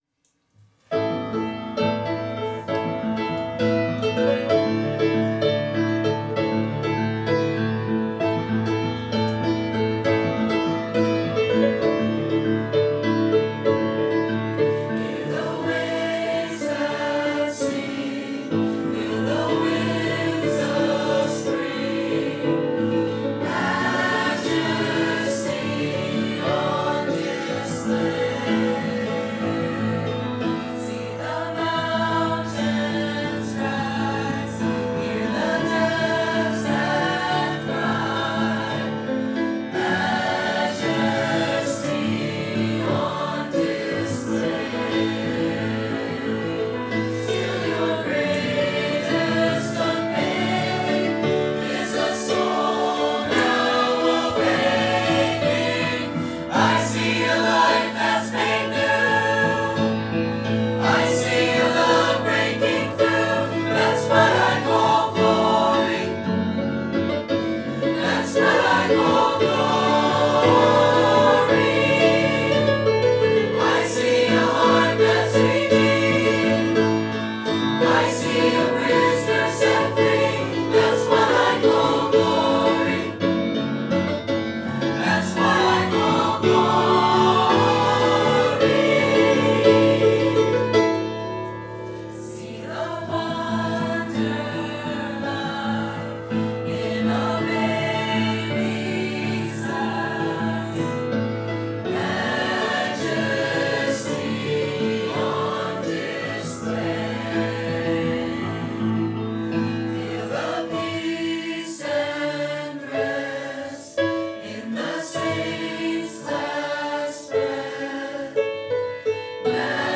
Adult Choir – That’s What I Call Glory